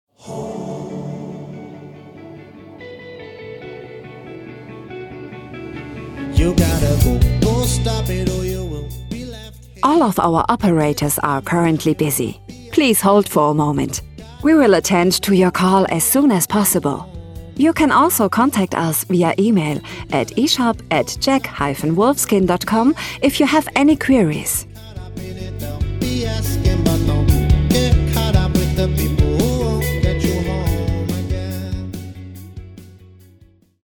Foreign Language Voice Samples
Commercial Demo
• Mikrofon: Neumann TLM 67 / Neumann TLM 103
• Acoustic Cabin : Studiobricks ONE
ContraltoMezzo-Soprano
ConfidentDynamicExperiencedFriendlyReliableTrustworthyVersatileYoungWarm